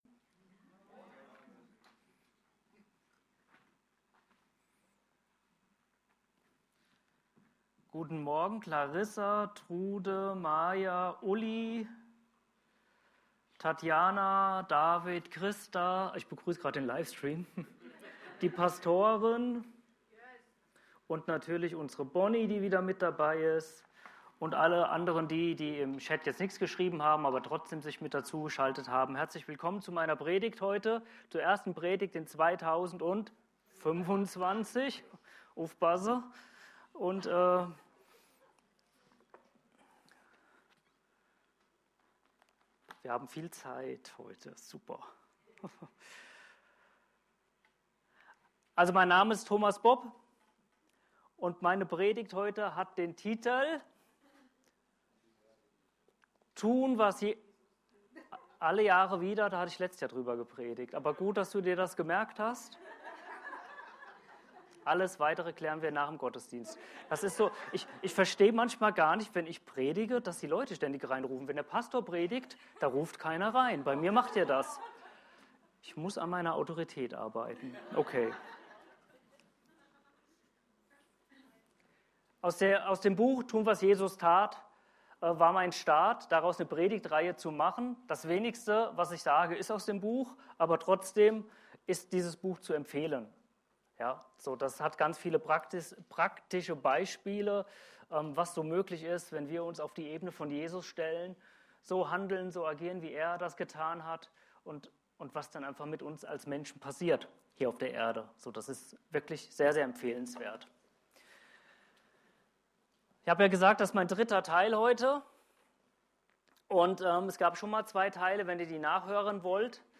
CCLM Predigten